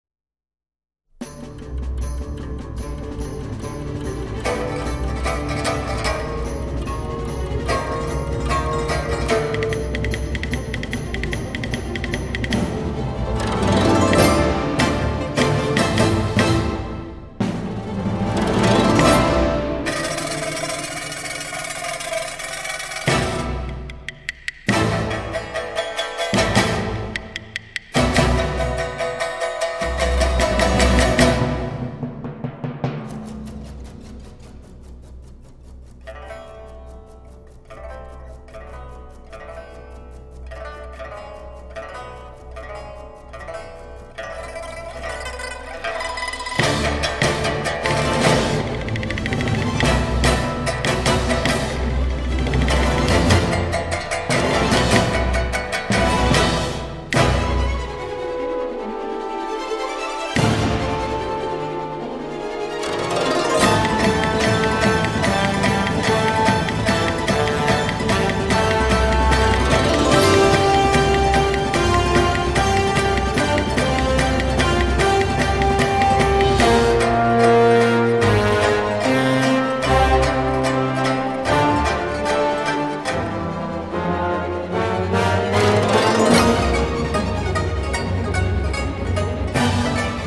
★ 大馬女聲與吉他組合最讓人迷醉的第四張專輯 !
獨創的編曲、絕佳的唱功、出色的錄音效果，刻畫出風情萬種的絕美人聲饗宴，音響系統測試最佳橋段！